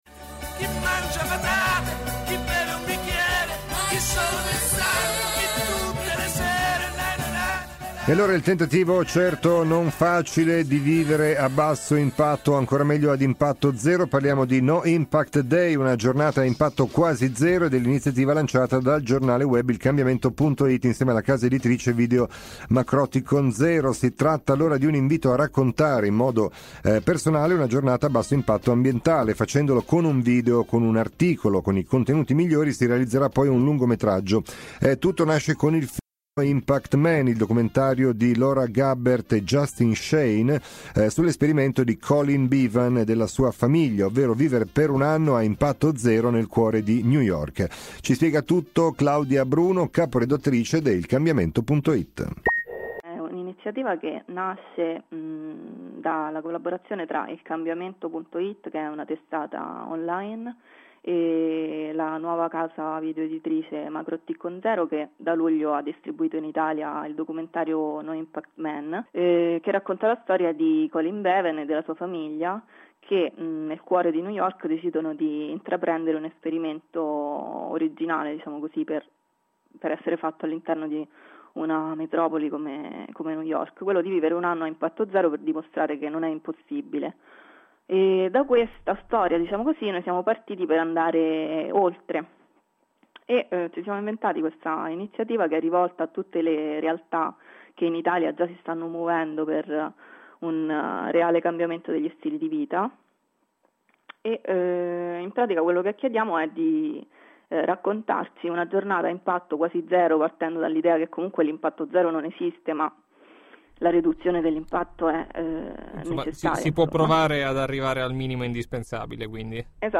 no_impact_day_ecoradio.mp3